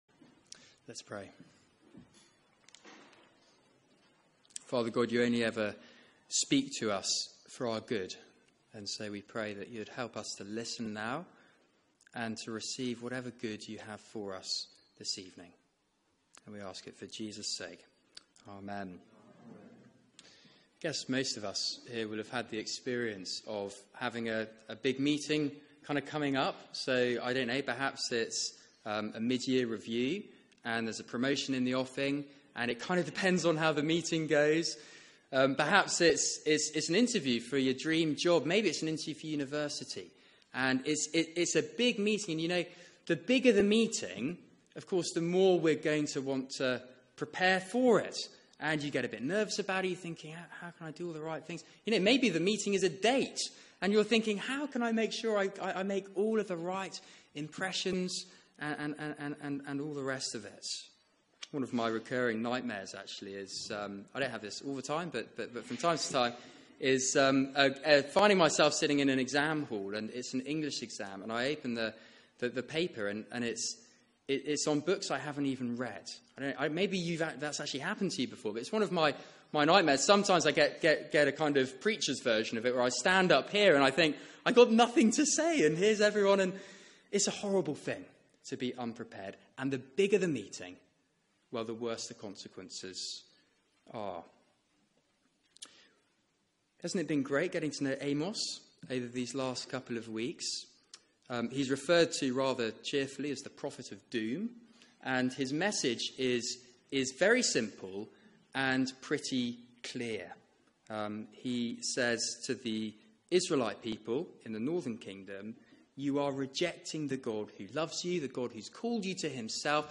Media for 6:30pm Service on Sun 19th Feb 2017 18:30 Speaker
Series: Prepare to meet your God Theme: Your God will meet you Sermon